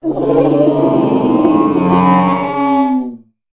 cetitan_ambient.ogg